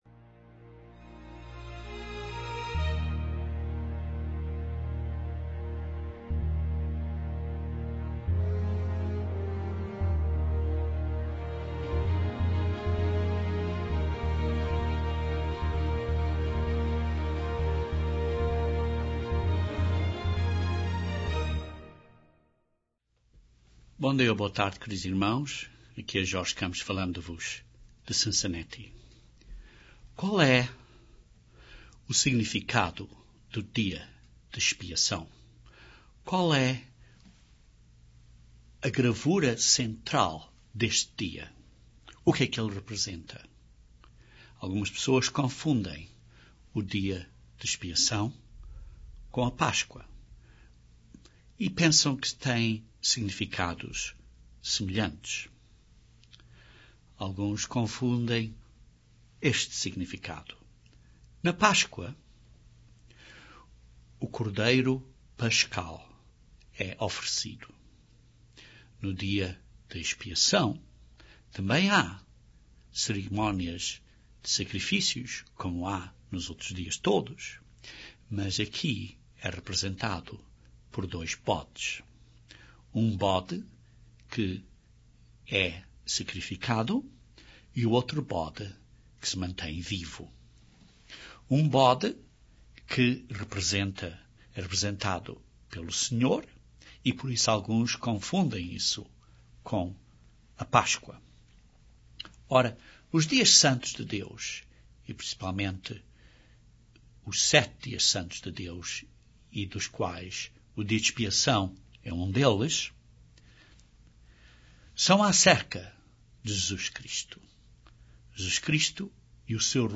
Este dia também representa a obra do Mediador no céu. Este sermão explica este grande significado e nos ajuda a entender este passo importante no Plano de Salvação de Deus.